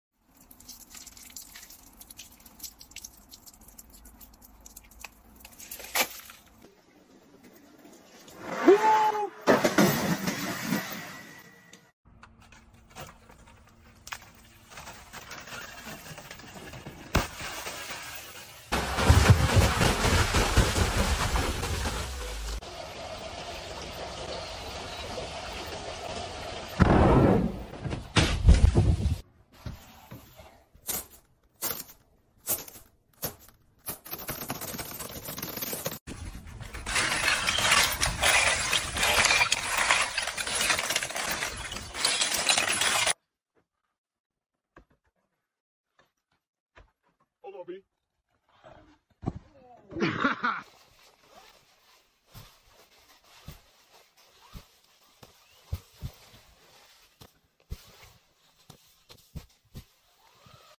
Snow falling on the sound effects free download
Snow falling on the roof